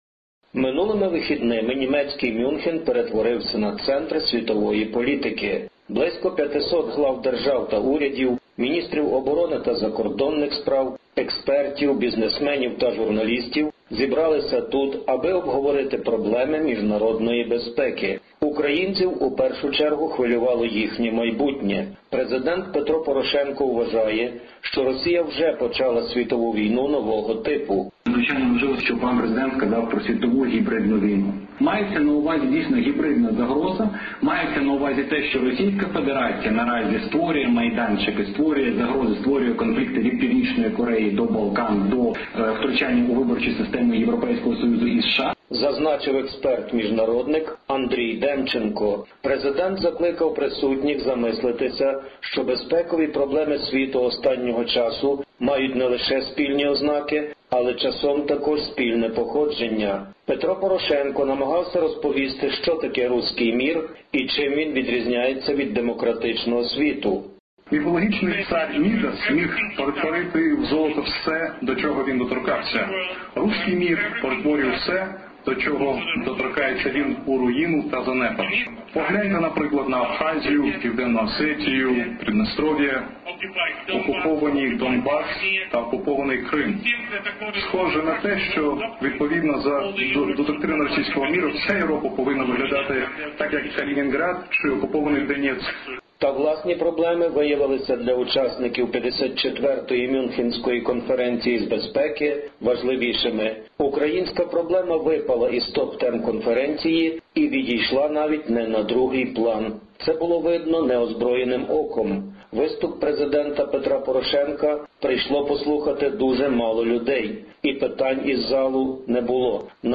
Mr Poroshenko, Munich Security Conference 2018.